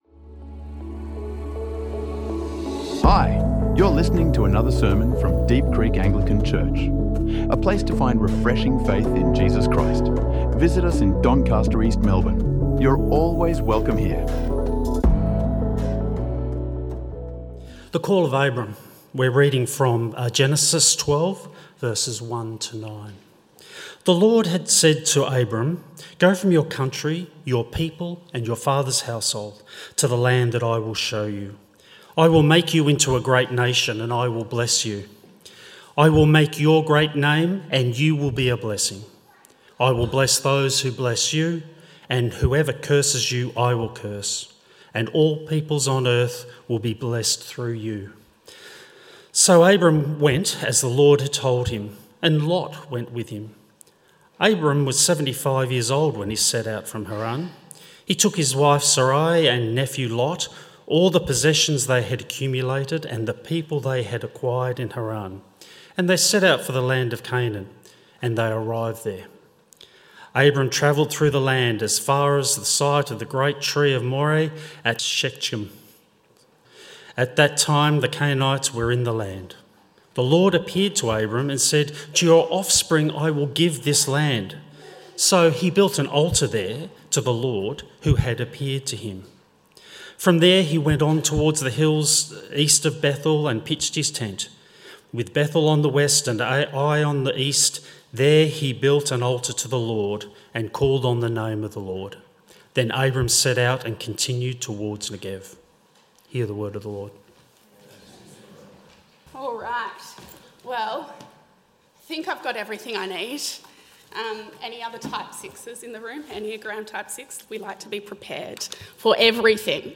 Explore Abraham’s journey of faith in Genesis 12. Learn how to walk faithfully with God into the unknown in this sermon from Deep Creek Anglican Church.